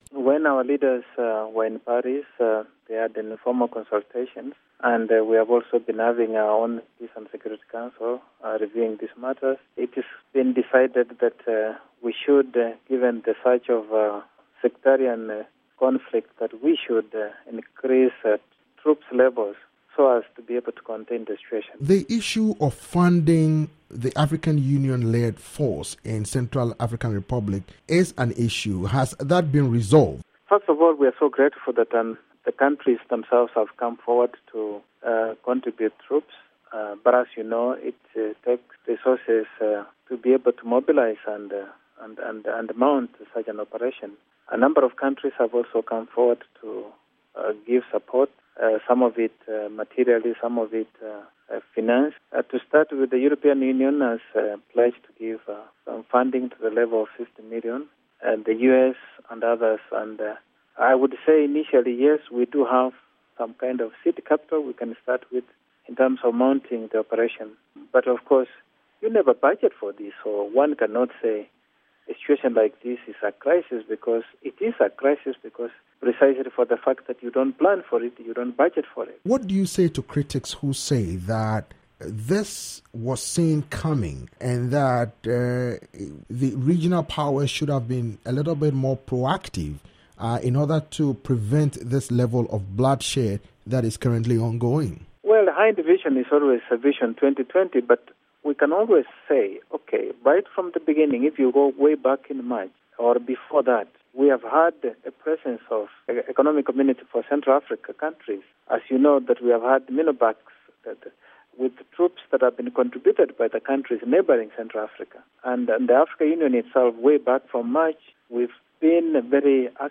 interview with Erastus Mwencha, AU deputy chairman